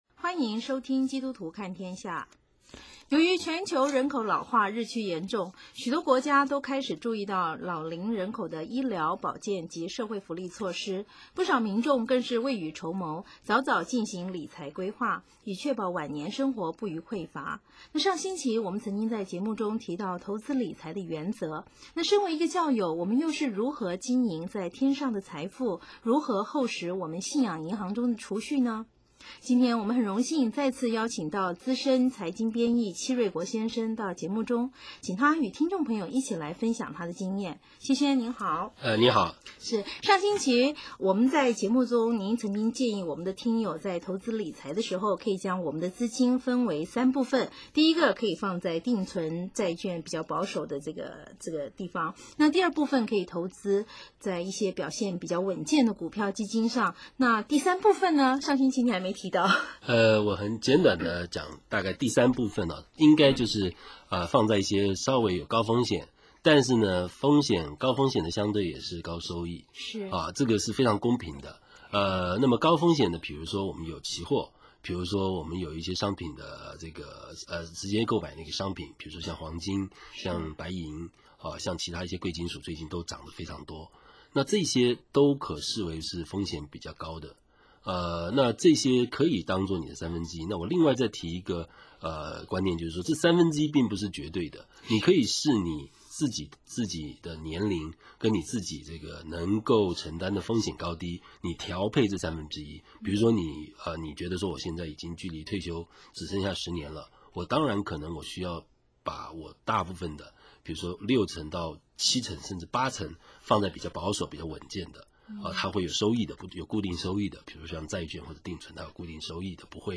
资深财经编译。